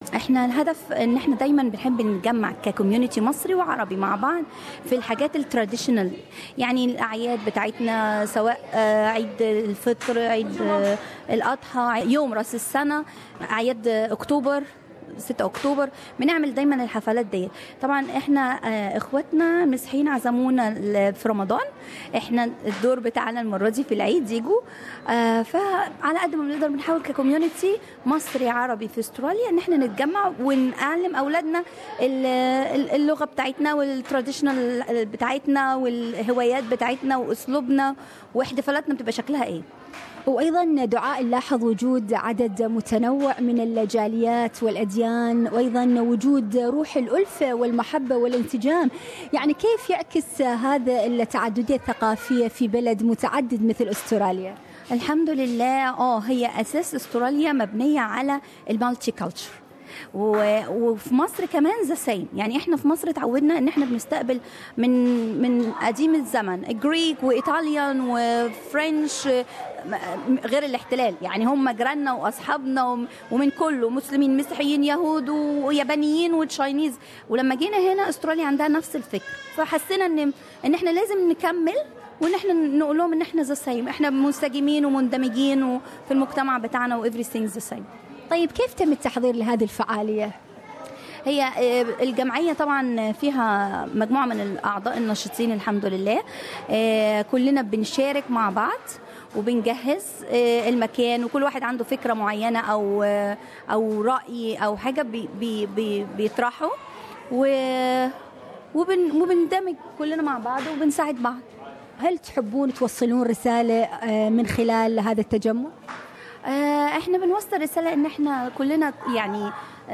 مهرجان عيد الفطر في ملبورن بحضور شخصياتِ رسمية ودينية متنوعة